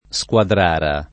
[ S k U adr # ra ]